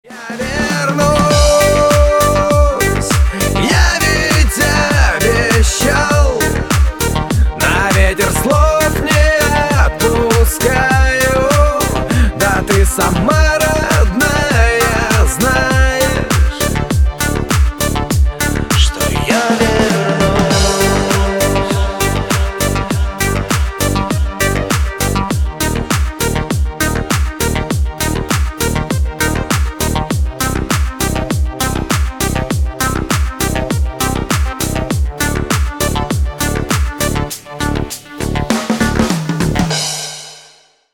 Рингтоны шансон релизов